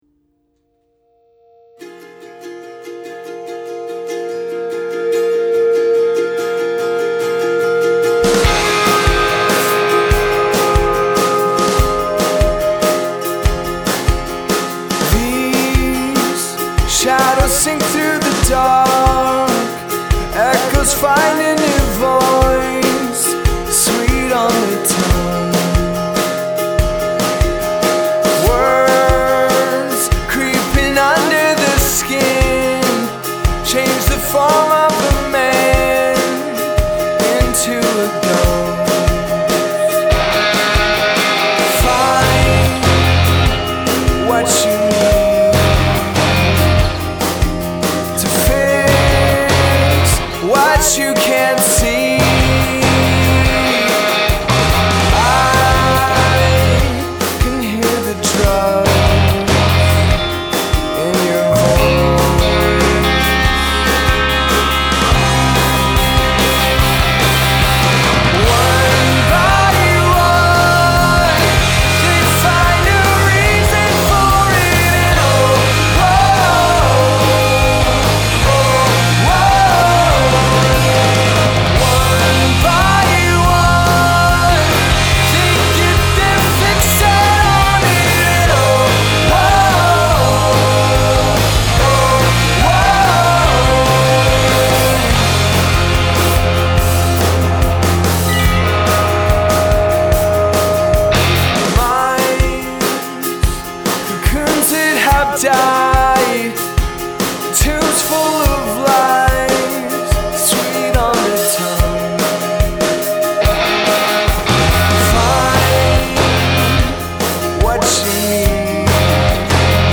Not the pop driven single but a good The Alarm type track.